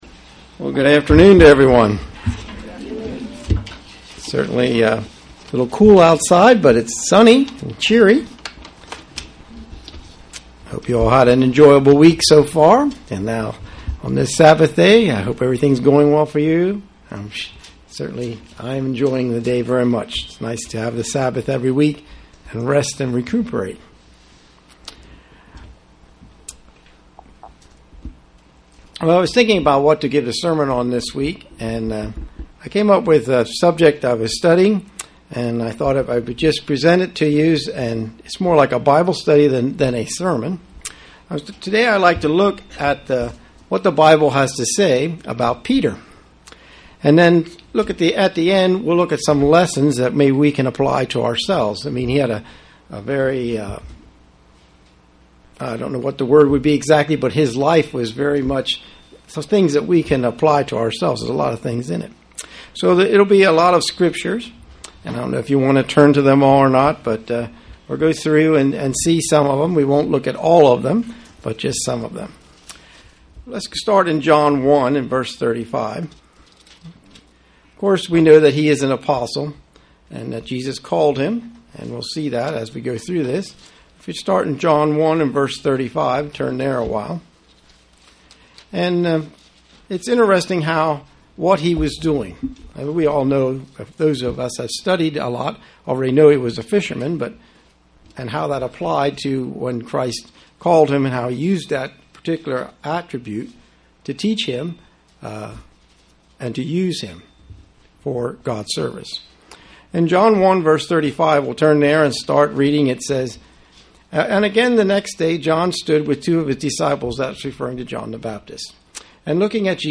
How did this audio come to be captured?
Given in York, PA